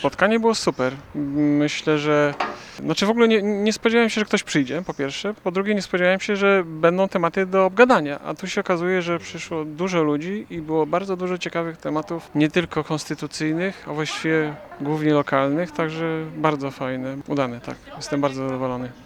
Uczestnicy spotkania z Rzecznikiem Praw Obywatelskich wychodzili z niego zadowoleni. Powinno być więcej takich inicjatyw, mówią mieszkańcy Giżycka.